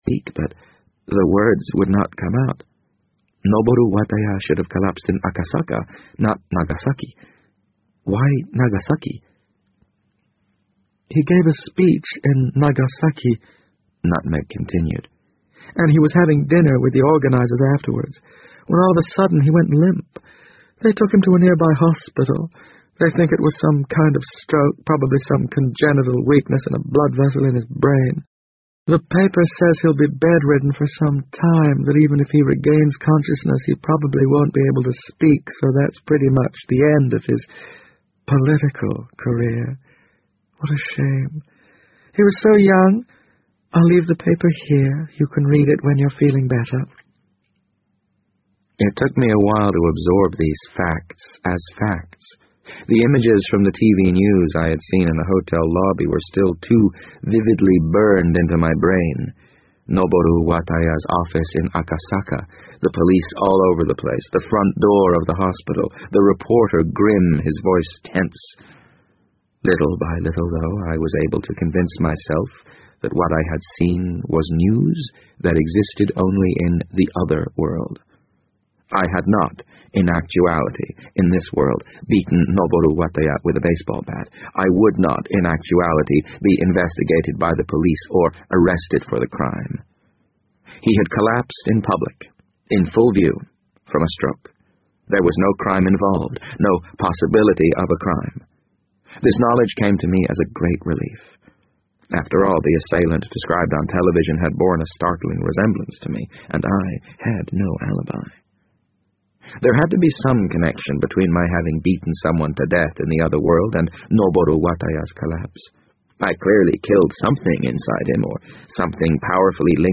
BBC英文广播剧在线听 The Wind Up Bird 015 - 16 听力文件下载—在线英语听力室